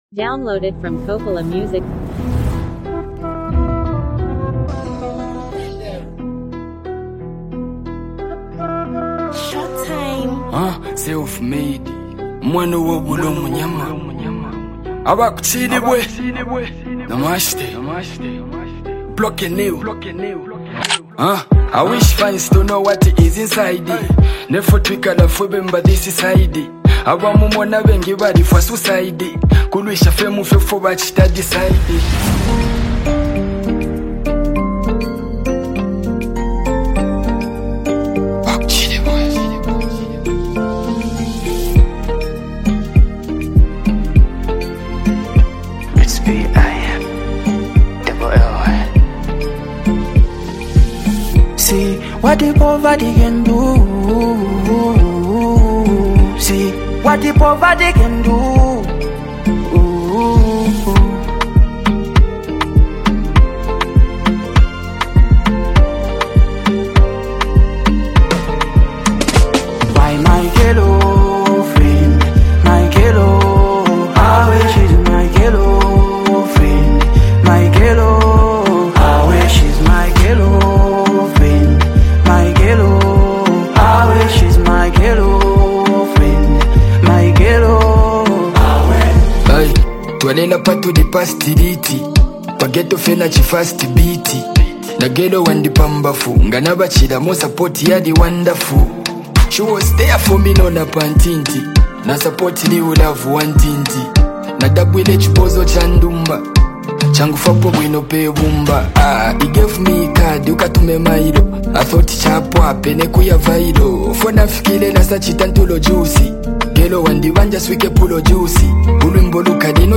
a deeply reflective and socially conscious song
By combining expressive vocals with meaningful storytelling